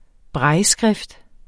Udtale [ ˈbʁɑjː- ]